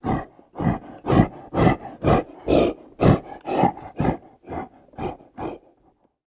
Animal Breath Large, Like Horse, Pig, Deep, Quick, Labored